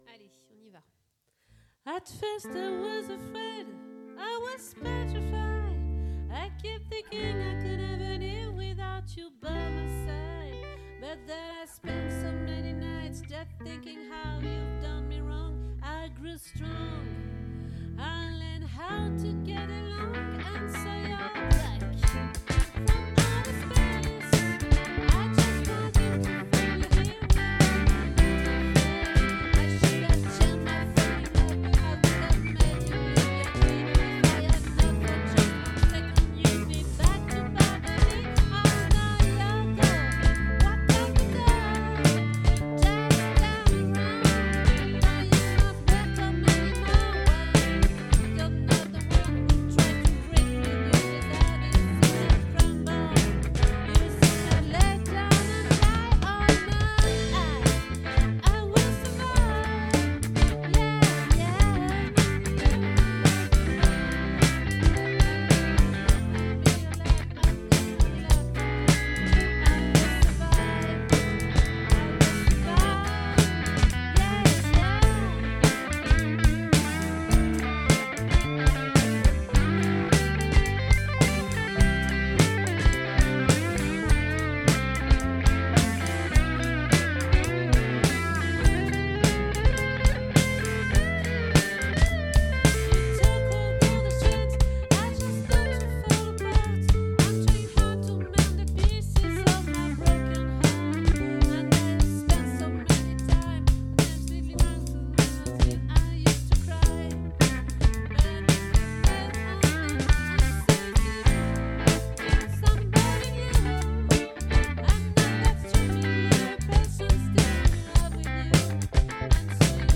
🏠 Accueil Repetitions Records_2025_01_06